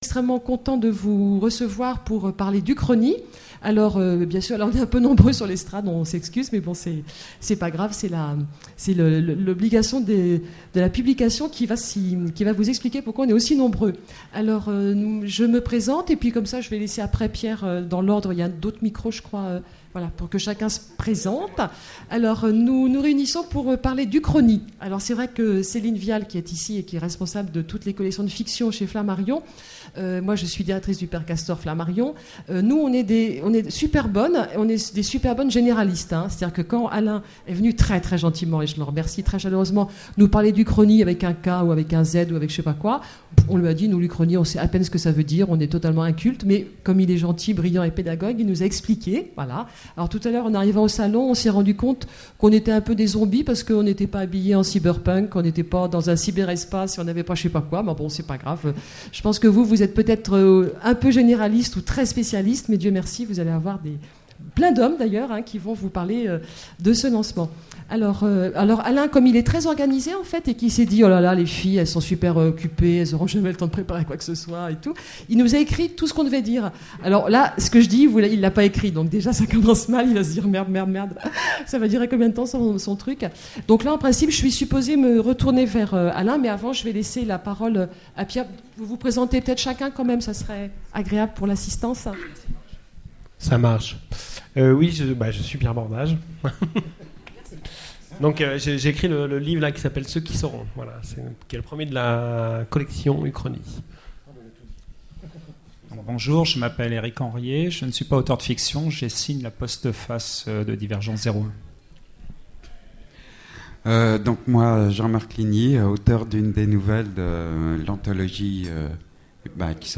Voici l'enregistrement de la conférence sur le lancement de la collection Ukronie aux Utopiales 2008.